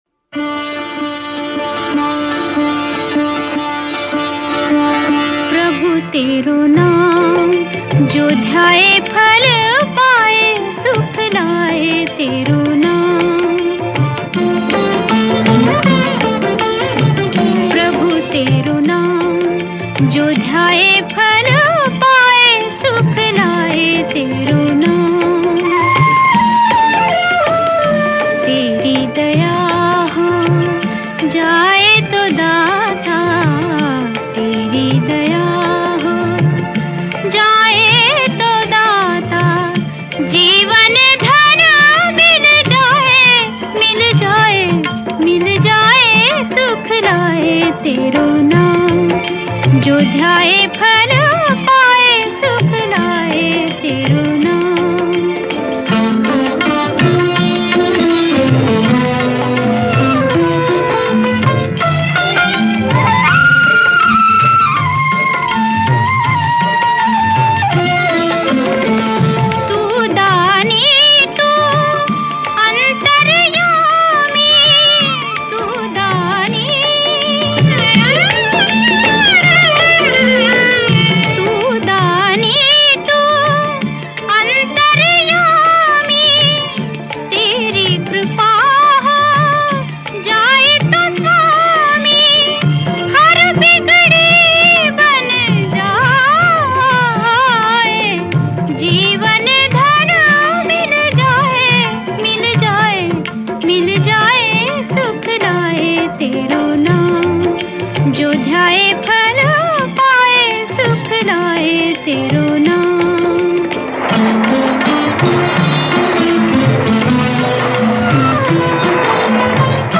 Devotional Single Songs - Bhajans